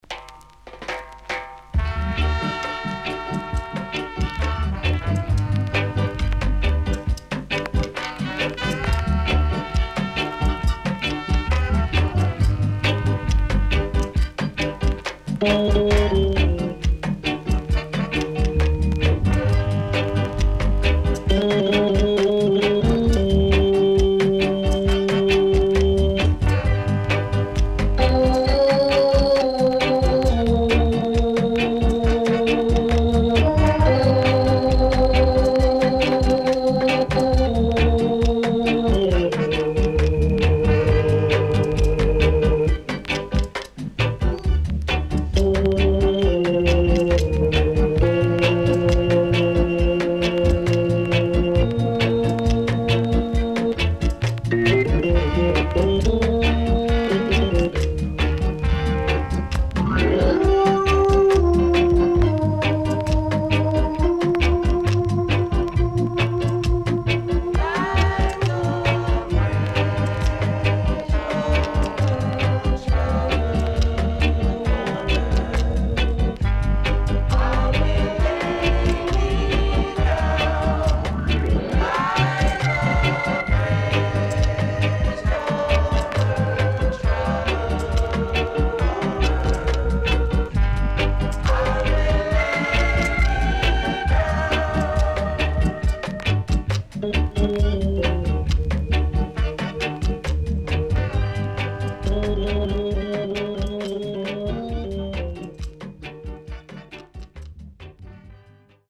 HOME > Back Order [VINTAGE 7inch]  >  SWEET REGGAE
SIDE A:所々チリノイズがあり、少しプチノイズ入ります。